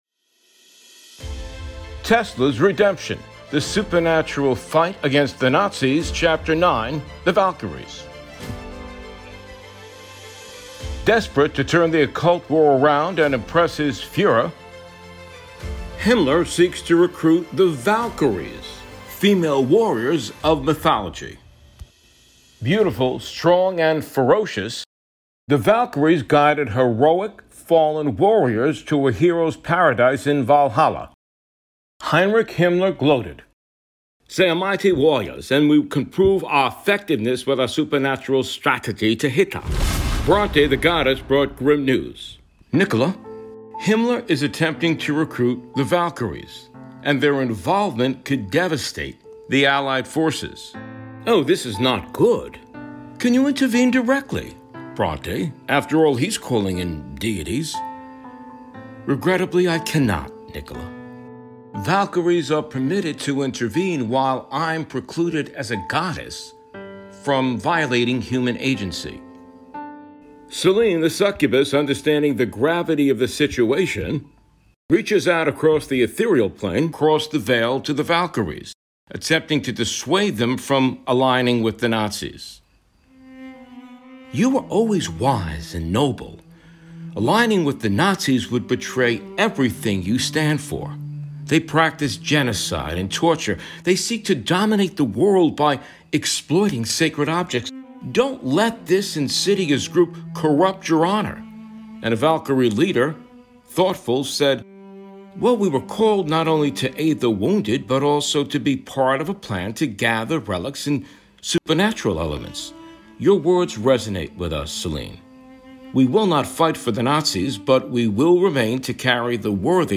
Format: Audio Book
Voices: Solo
Narrator: Third Person
Soundscape: Sound effects & music